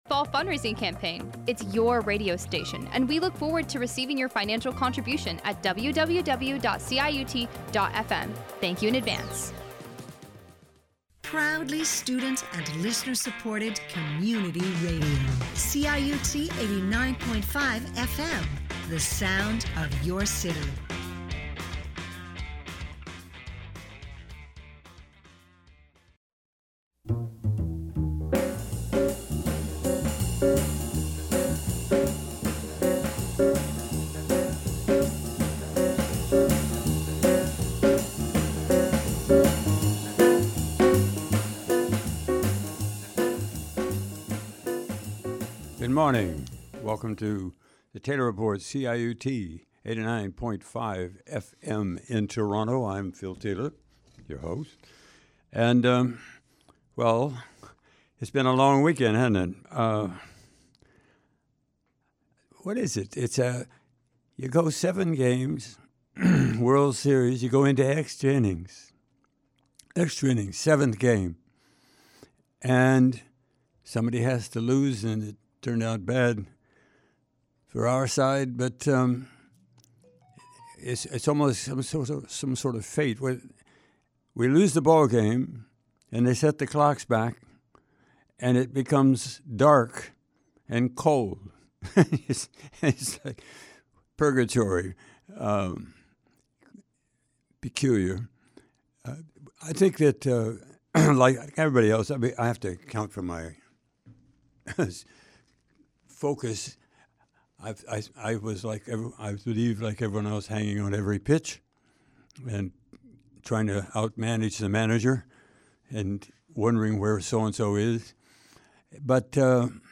Taylor Report commentary